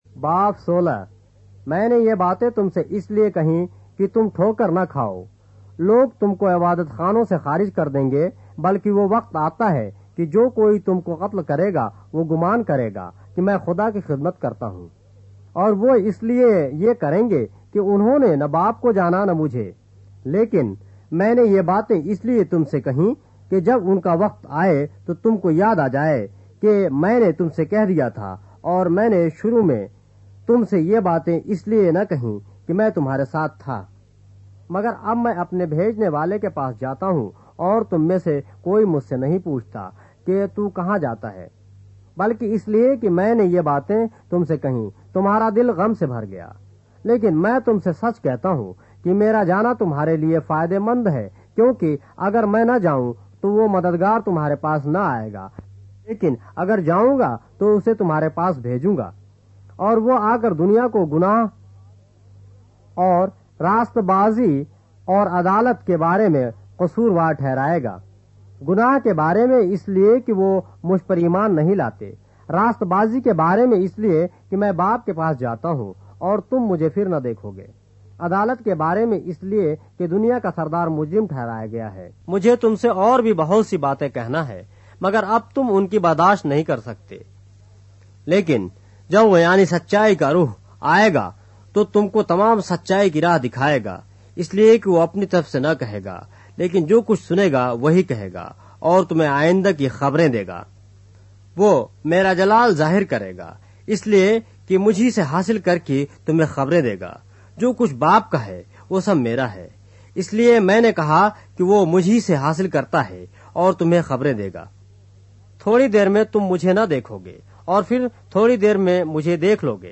اردو بائبل کے باب - آڈیو روایت کے ساتھ - John, chapter 16 of the Holy Bible in Urdu